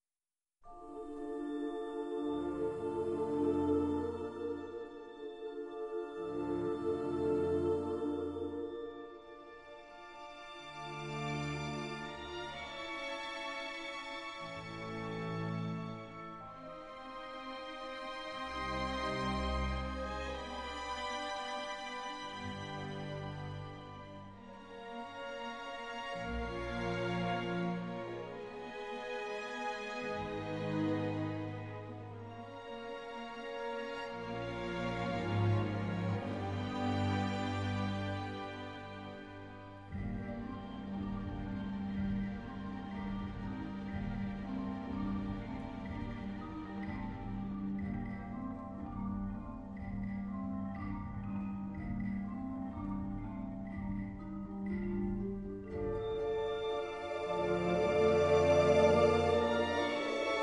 digital stereo session masters for dynamic audio experience
Orchestral Score